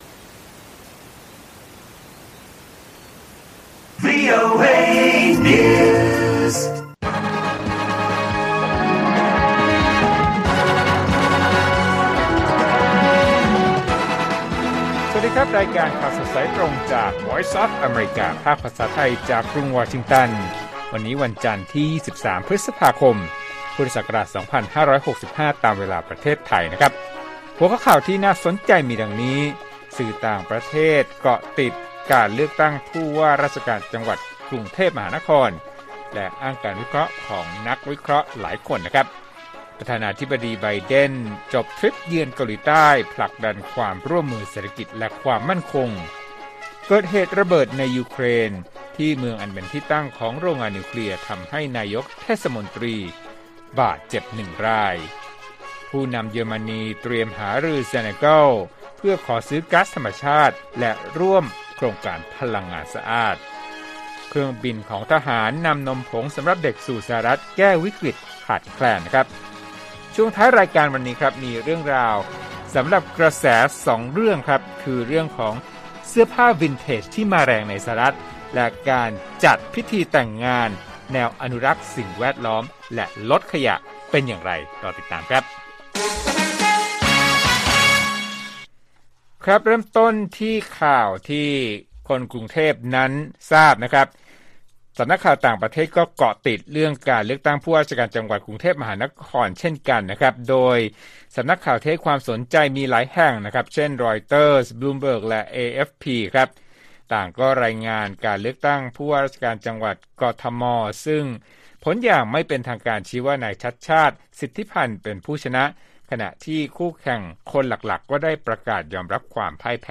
ข่าวสดสายตรงจากวีโอเอ ภาคภาษาไทย 6:30 – 7:00 น. 23 พฤษภาคม 2565